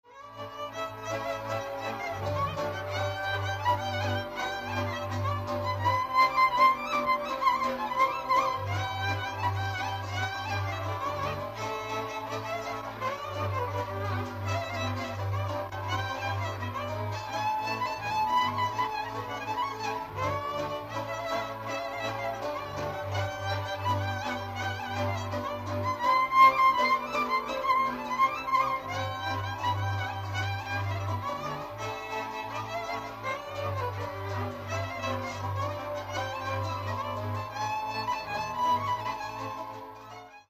Dallampélda: Hangszeres felvétel
Erdély - Maros-Torda vm. - Mezőbánd
Műfaj: Jártatós
Stílus: 3. Pszalmodizáló stílusú dallamok